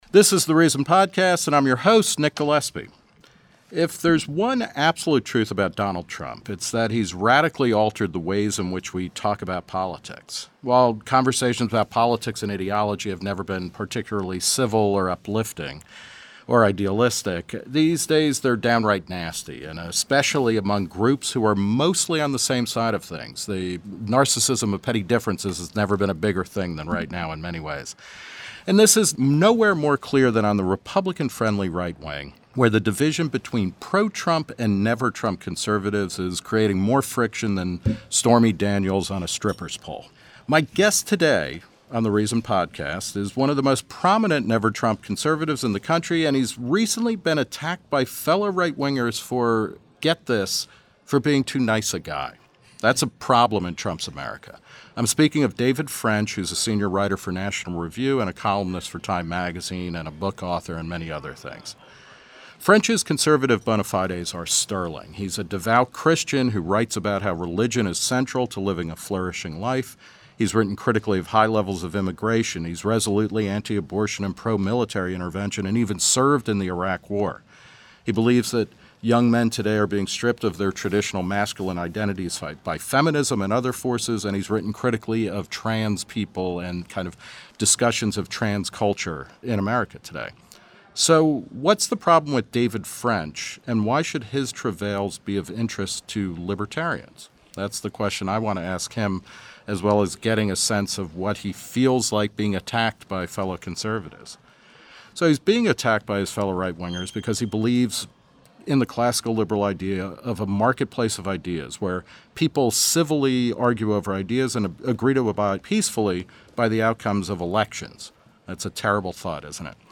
Today's guest is David French, a senior writer for National Review and a columnist for Time.
Nick Gillespie | 6.19.2019 12:15 PM Share on Facebook Share on X Share on Reddit Share by email Print friendly version Copy page URL Add Reason to Google Media Contact & Reprint Requests National Review's David French talks with Reason's Nick Gillespie at FEECon 2019.